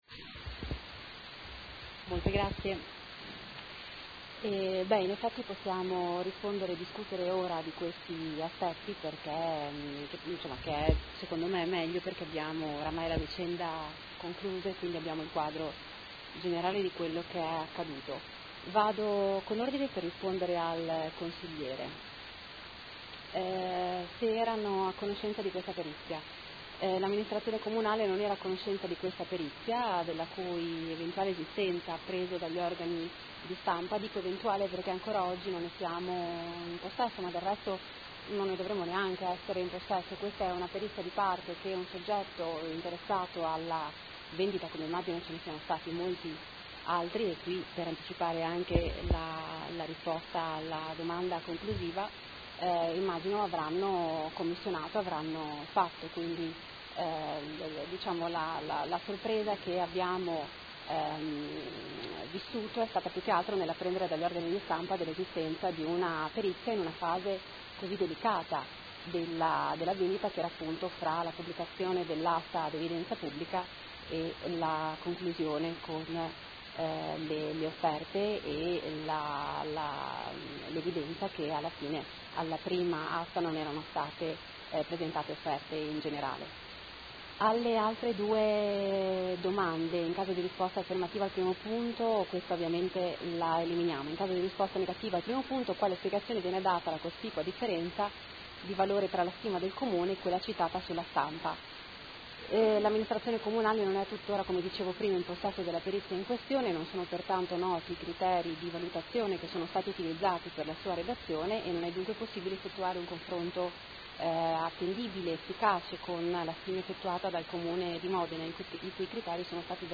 Seduta del 22 ottobre. Interrogazione del Gruppo Consiliare Movimento 5 Stelle avente per oggetto: Informazioni a corredo del bando di vendita azioni Farmacie Comunali. Risponde l'assessora